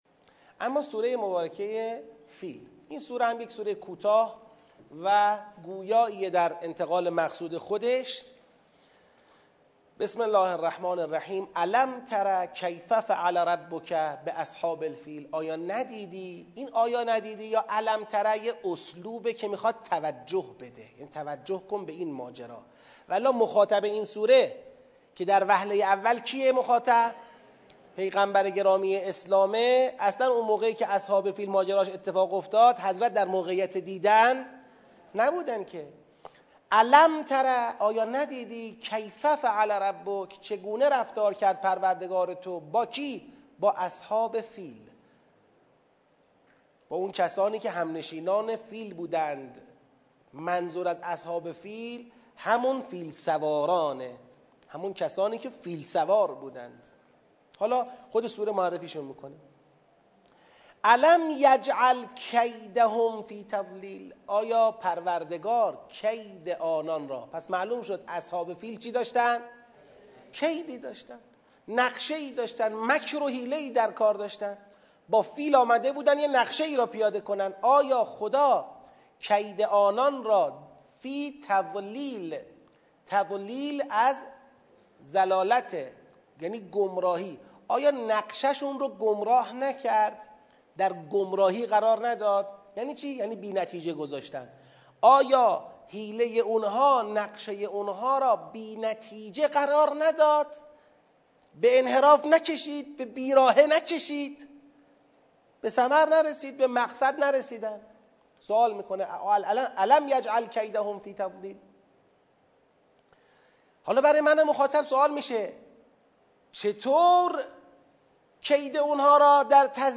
آموزش تدبر در سوره فیل